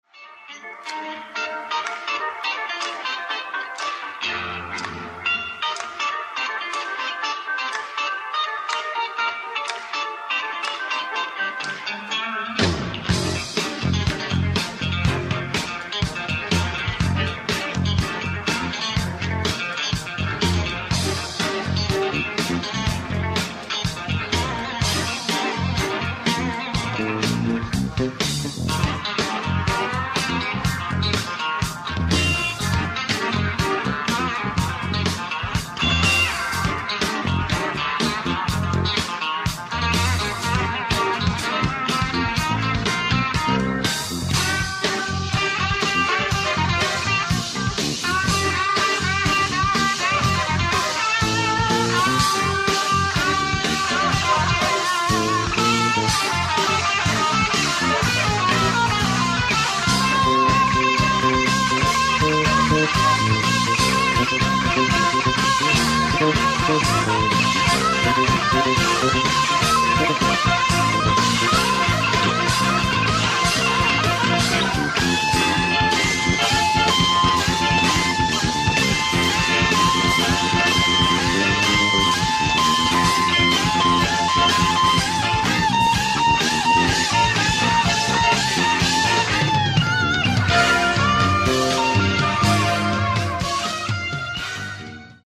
ライブ・アット・郵便貯金ホール、東京 01/17/1981
※試聴用に実際より音質を落としています。